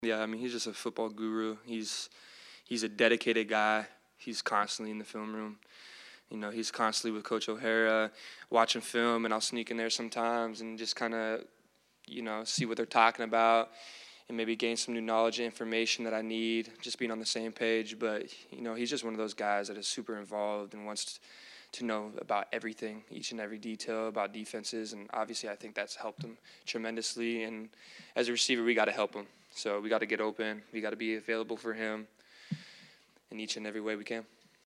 Florida quarterback Graham Mertz will help lead the way, Pearsall said.